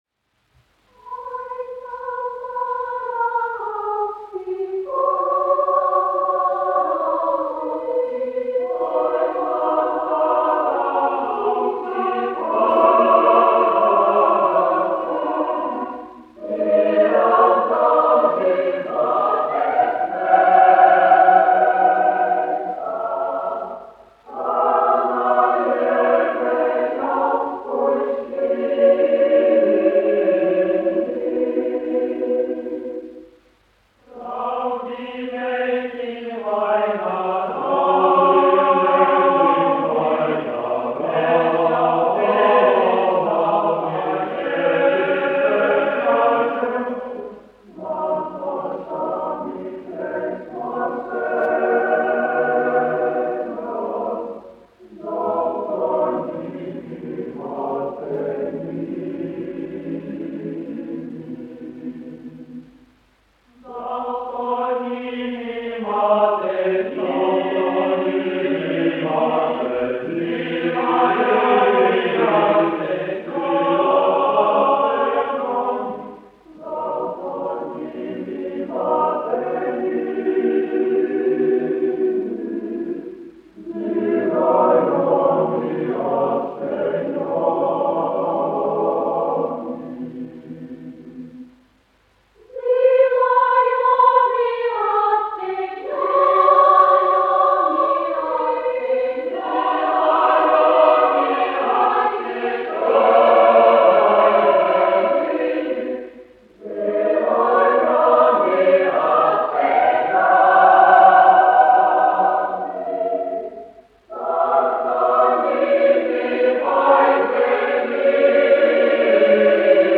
Daugavpils Kultūras nama Nr. 1 jauktais koris, izpildītājs
1 skpl. : analogs, 78 apgr/min, mono ; 25 cm
Latgaliešu tautasdziesmas
Kori (jauktie)
Latvijas vēsturiskie šellaka skaņuplašu ieraksti (Kolekcija)